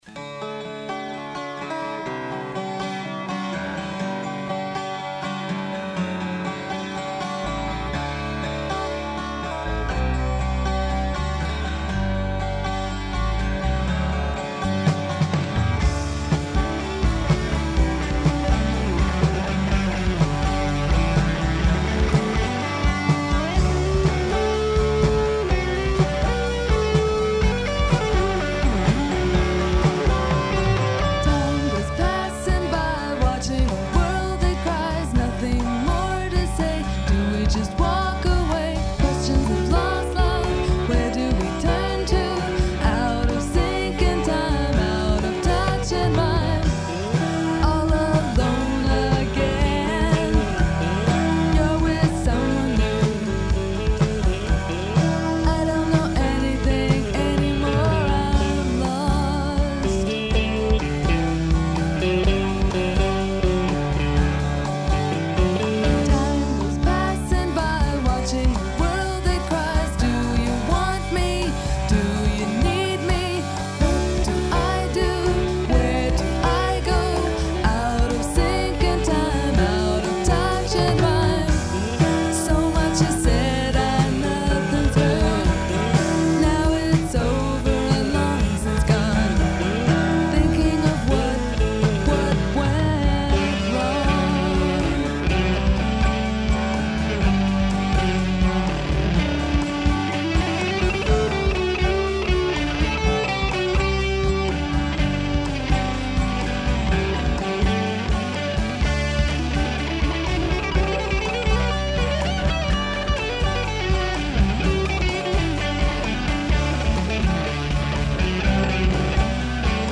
pop songs
rhythm guitar
drums
vocals
bass